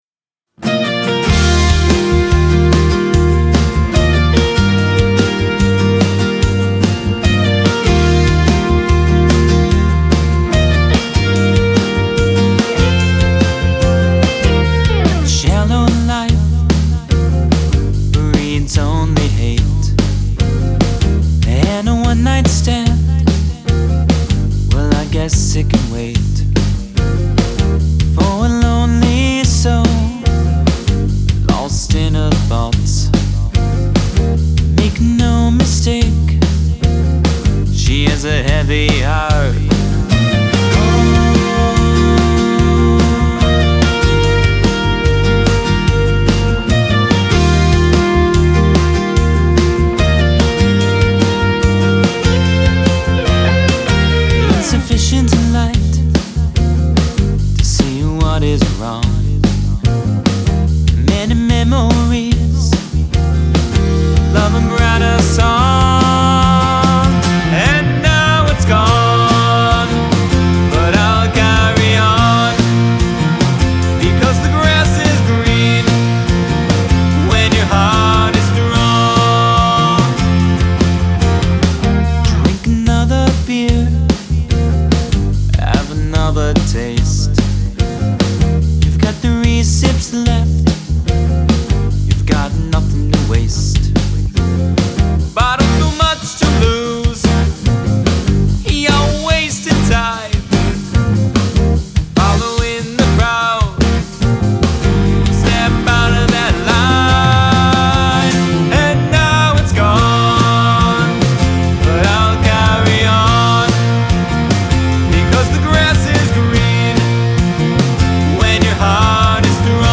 "When Your Heart" - adult contemporary
Chorus harmonies help the song tremendously. We're trying a subtle rock piano in the very final chorus to bring it home, but debating it.